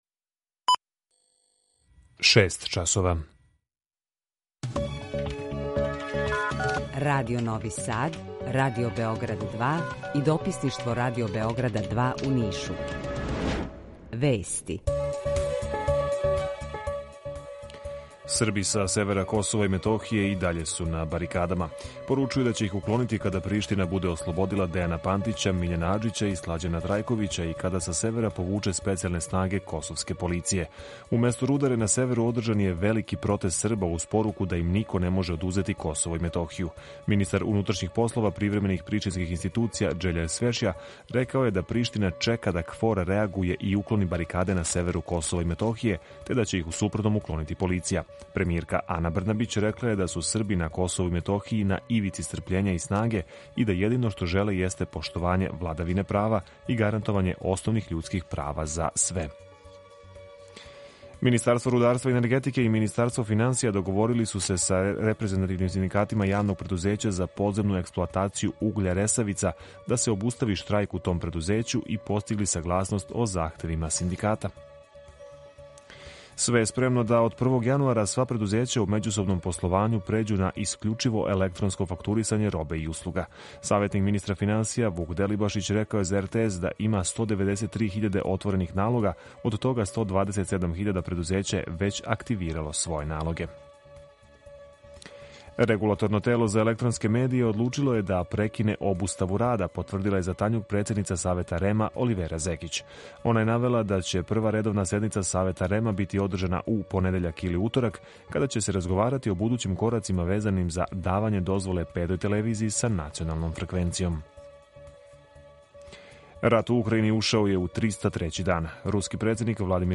Четири градоначелника уживо у програму из четири студија – Београд, Нови Сад, Ниш и Бањалука
У два сата, ту је и добра музика, другачија у односу на остале радио-станице.